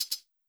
GAR Closed Hat 16th.wav